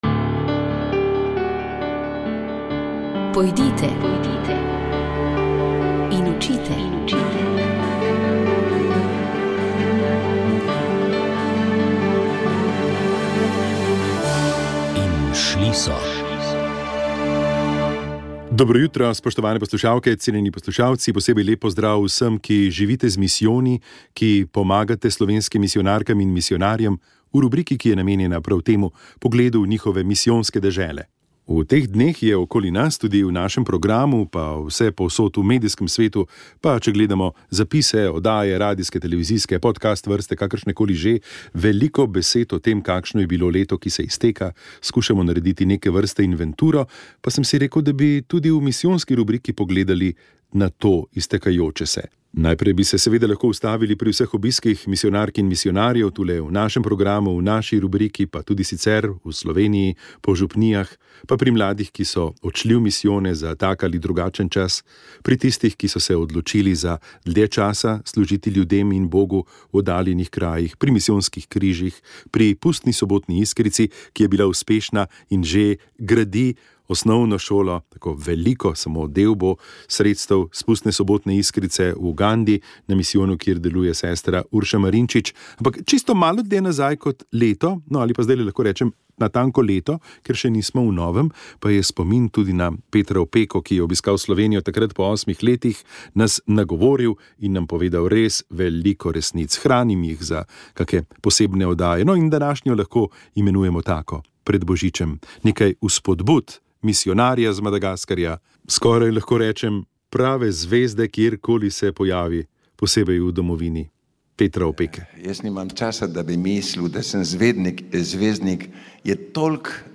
Na vrsti je bila že 67. radijska kateheza za bolnike in starejše.